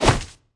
日光浴野蛮人会挥砍手里的太阳伞攻击对手，是一个近战单位。
Media:sunburn_barbarian_atk_03.wav 攻击音效 atk 局内攻击音效
Sunburn_barbarian_atk_01.wav